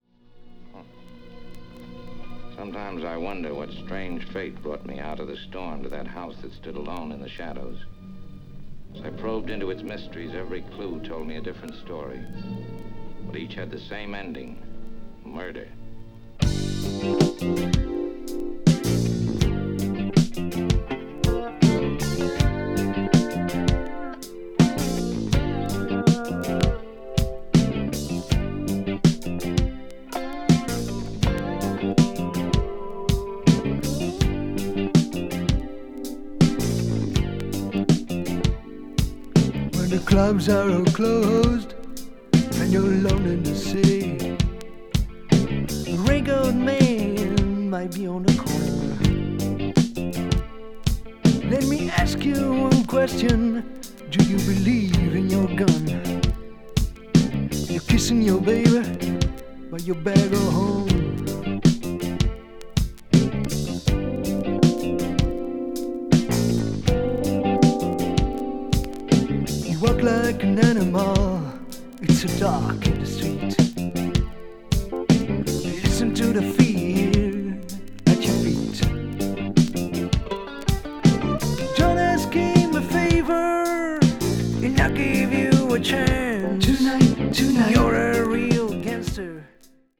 laid-back bluesy groove
a.o.r.   blues rock   country rock   folk rock